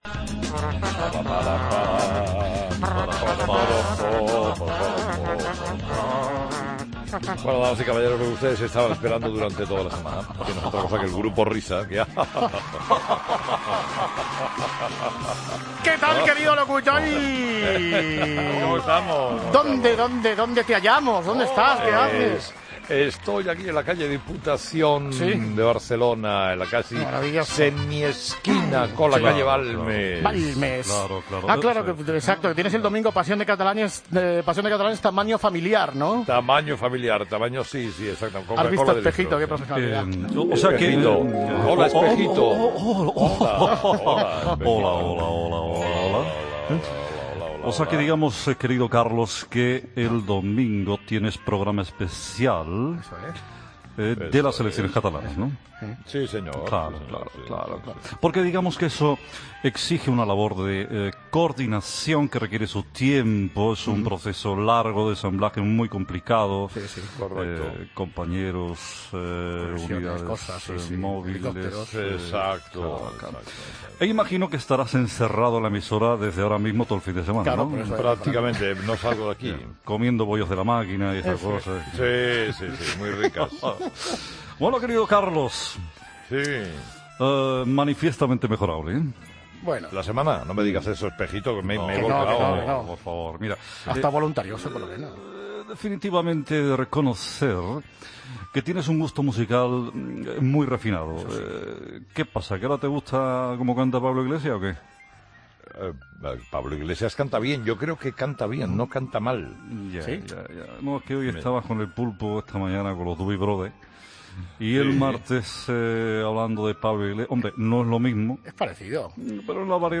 AUDIO: El rap de Pablo Iglesias y sus duetos con Jim Morrison y Mariano Rajoy. Luis del Olmo también ha vuelto y Montoro saluda a Herrara.